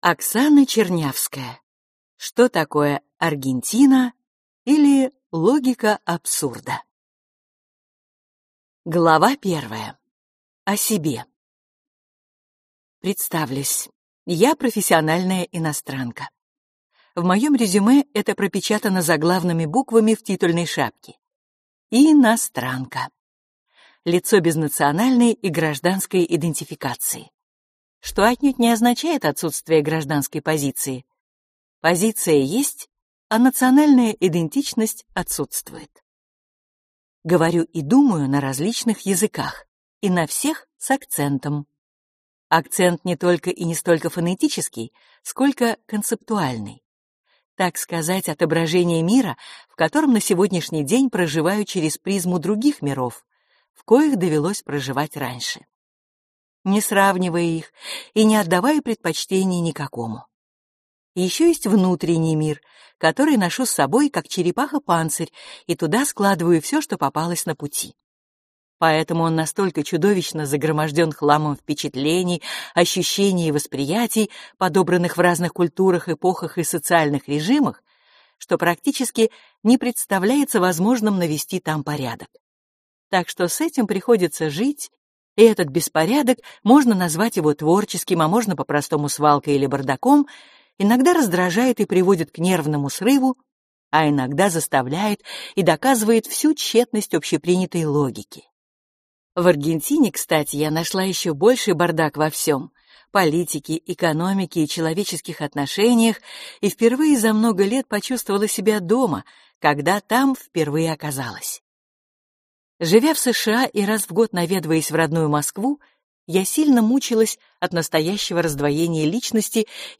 Аудиокнига Что такое Аргентина, или Логика абсурда | Библиотека аудиокниг